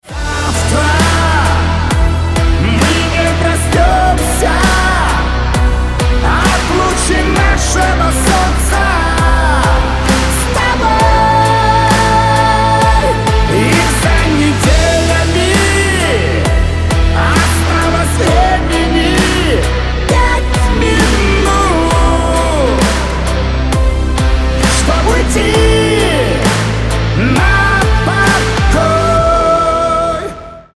• Качество: 320, Stereo
мужской голос
лирика
медленные